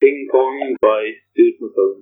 This game was recovered from a back-up cassette.
The creator of the back-up cassette recorded an audio description prior to the program that indicates the program's name and publisher.